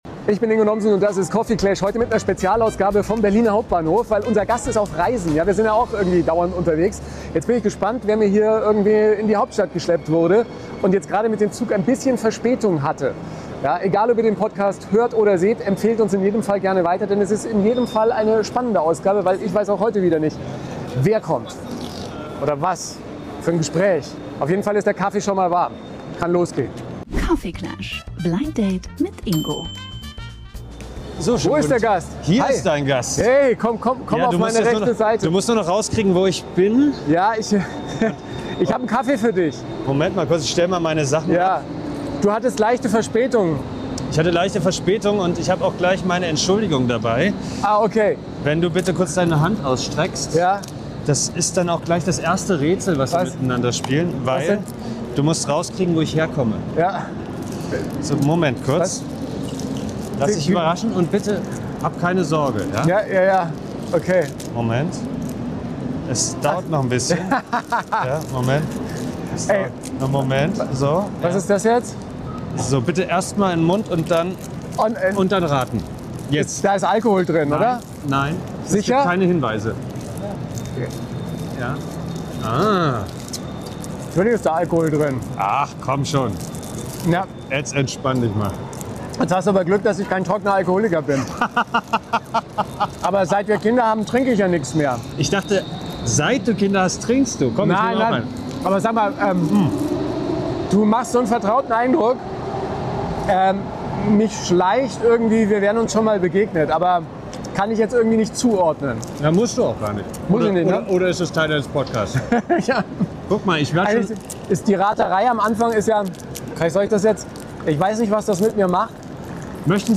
Schauspieler Franz Dinda reist an und packt Geschichten aus. Zwischen Kunst, Casting, Karriere und Kindern. Dazu ein Spiel, bei dem Wörter mehr Stolperfallen haben als ein Bahnsteig bei Regen.